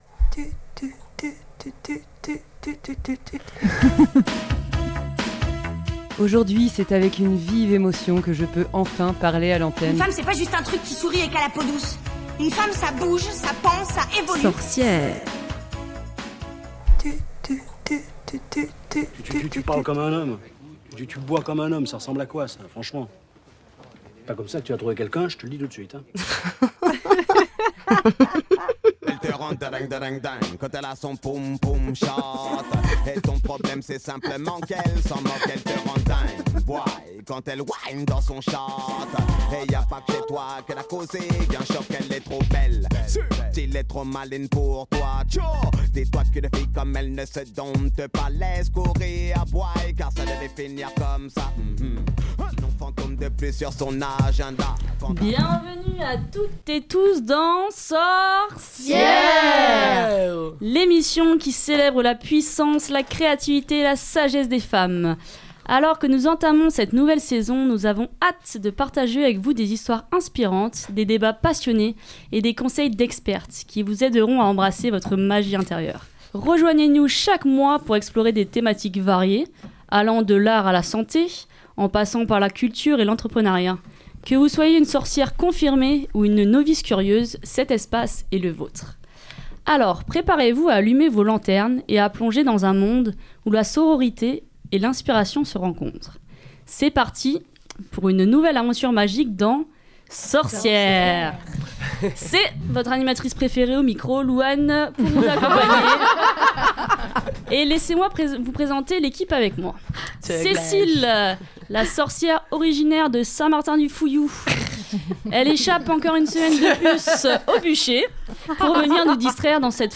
Sorcières?... c'est une émission avec des femmes, faites par des femmes qui s'adressent à toutes et tous.
Pour clôturer cette saison en beauté, on se retrouve pour une émission spéciale "hors les murs" à la guinguette du Moulin de Coupeau.
Et pour terminer en beauté, sorcières et guinguette ont dansé au son d’un DJ set vinyle.